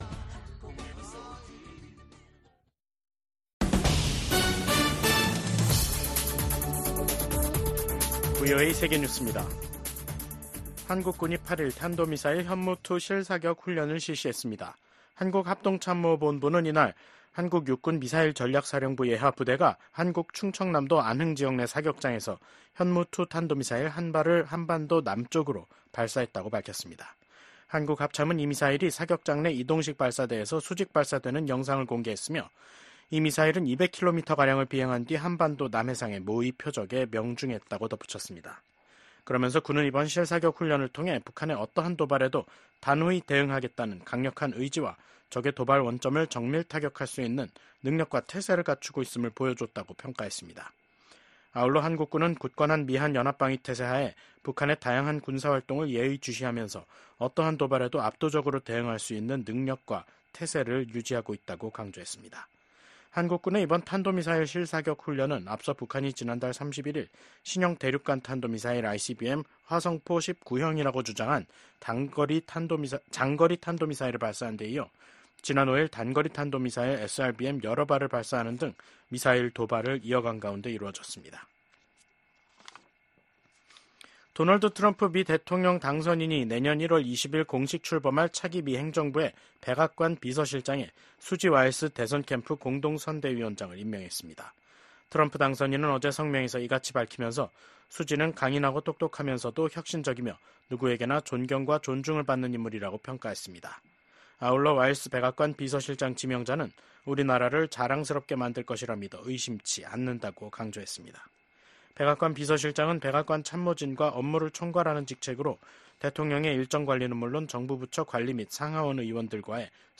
VOA 한국어 간판 뉴스 프로그램 '뉴스 투데이', 2024년 11월 8일 3부 방송입니다. 조 바이든 미국 대통령이 미국인들의 선택을 수용해야 한다며 도널드 트럼프 당선인의 대선 승리를 축하했습니다. 블라디미르 푸틴 러시아 대통령은 북한과의 합동 군사훈련이 가능하다고 밝혔습니다. 미국 국방부는 러시아에 파병된 북한군이 전장에 투입되면 합법적인 공격 대상이 된다고 경고했습니다.